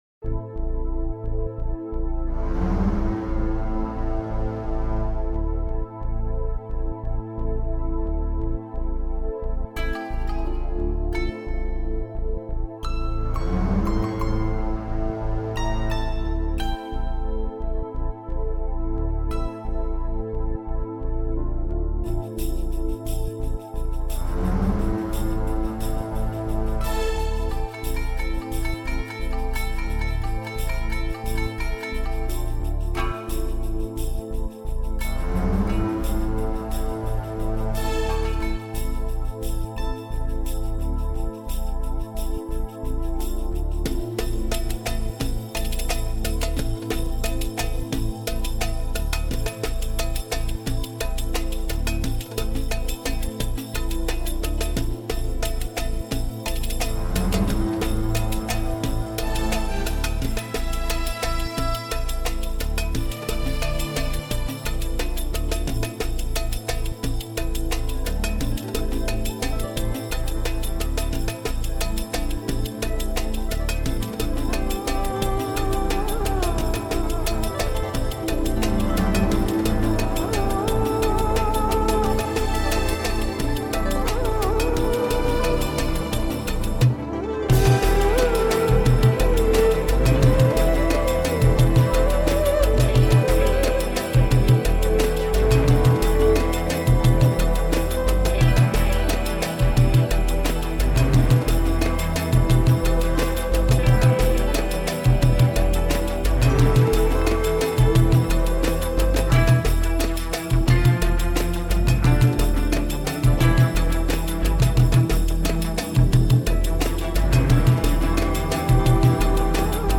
土耳其风情